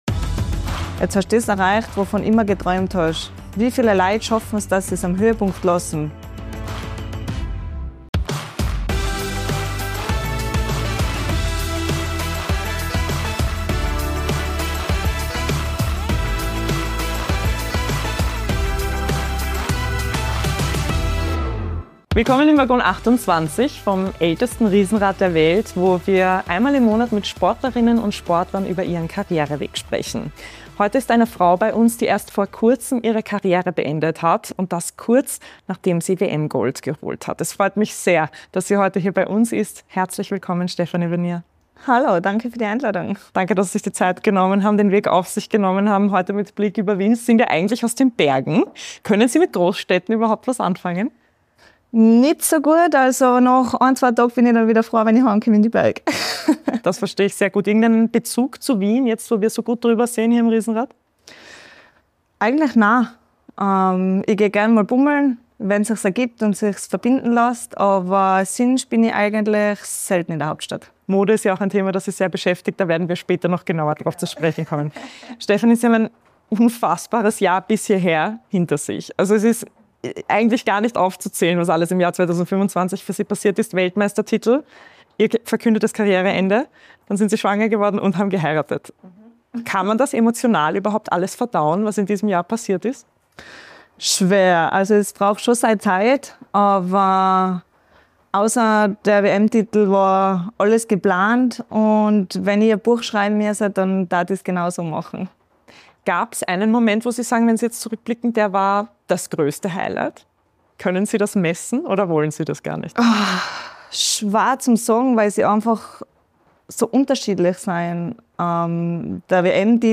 Talk Format